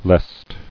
[lest]